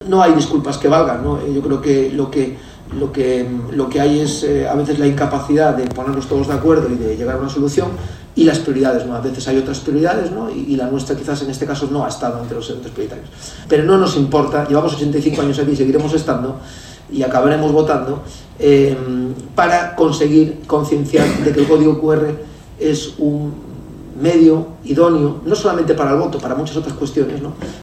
Posteriormente tuvo lugar una mesa redonda en la que intervinieron los eurodiputados